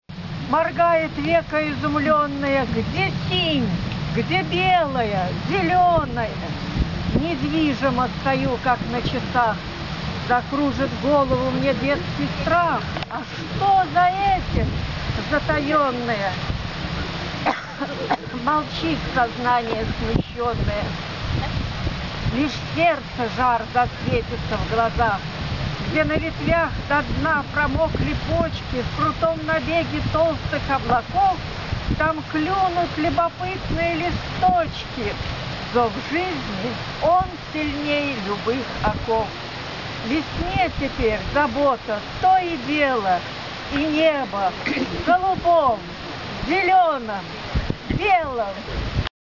На Марьином утесе была организована трапеза и продолжились выступления участников Пришвинских чтений.